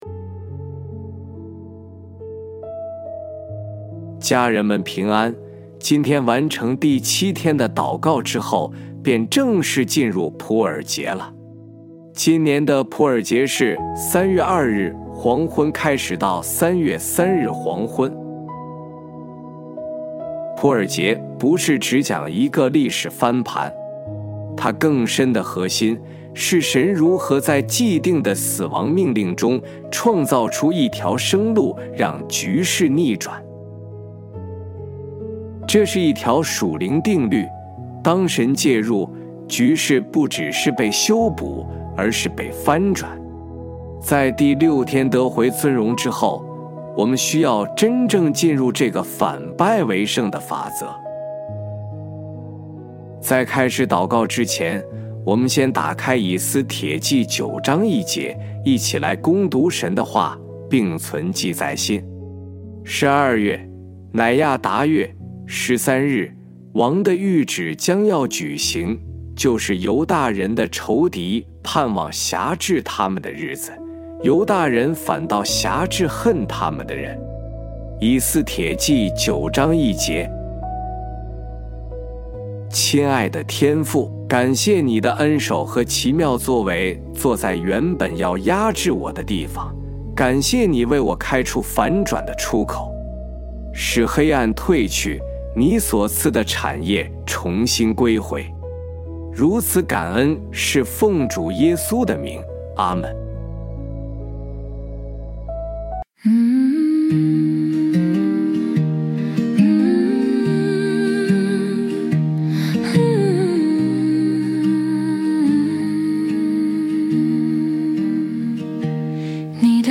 一起祷告 亲爱的天父， 今天我们和在线聆听的家人们一同站在第七天，不只是为翻转欢呼，更要明白翻转的律。